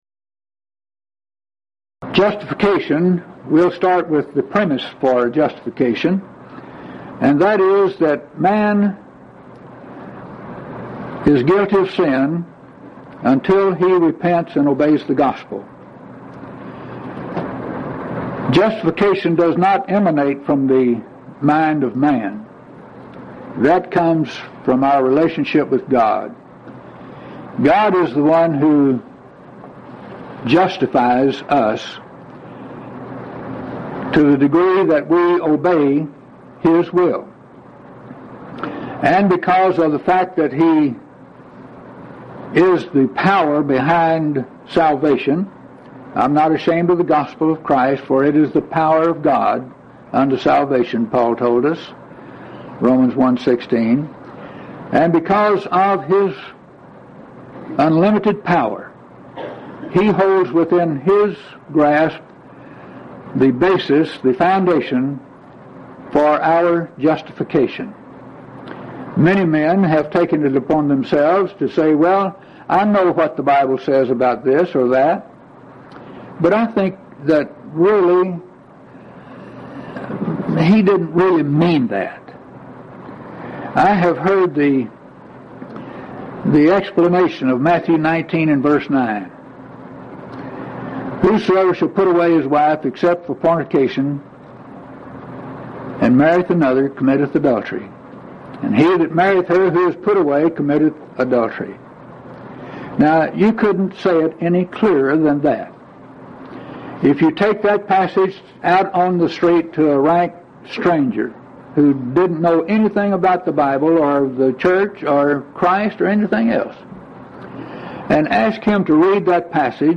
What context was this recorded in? Event: 2nd Annual Lubbock Lectures